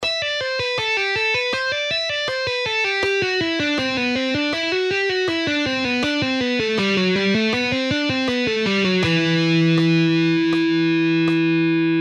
E minor Legato Run:
Half Speed:
4.-Legato-Exercise-In-Em-Scale-Half-Speed.mp3